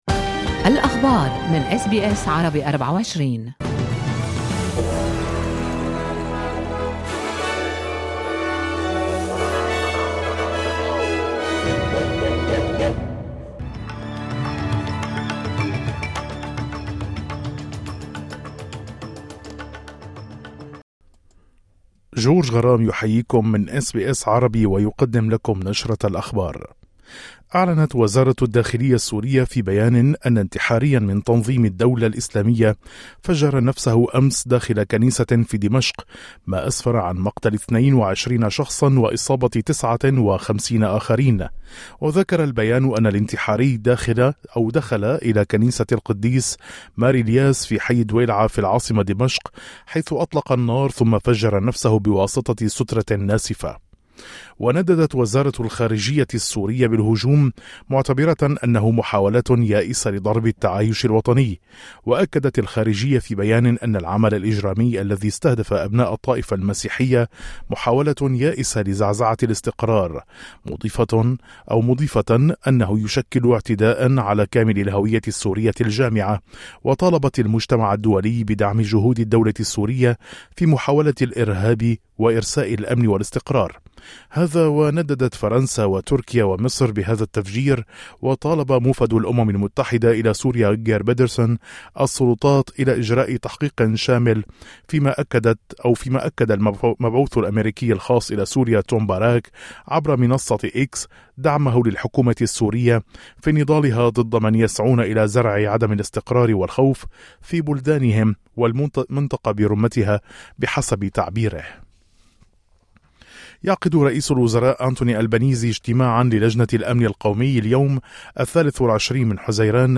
نشرة أخبار الظهيرة 23/06/2025